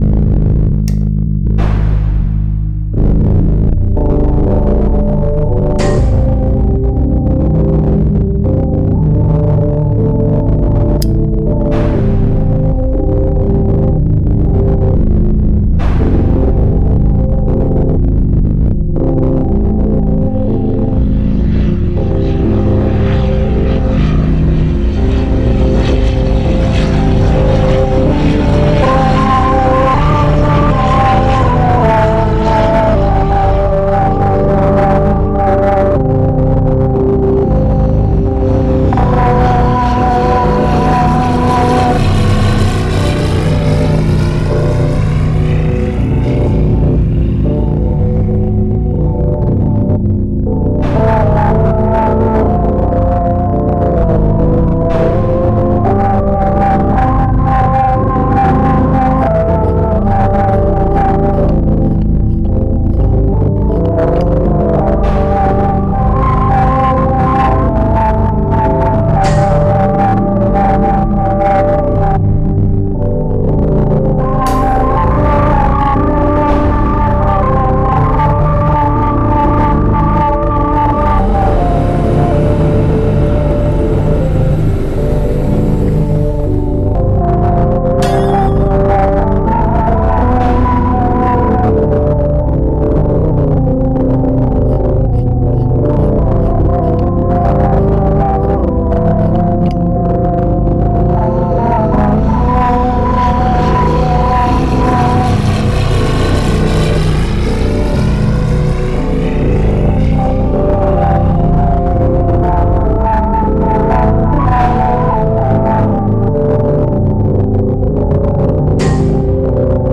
256SOUND consists out of a visual and AI generated auditory artwork, derived from the data in the genesis 256 ART pieces.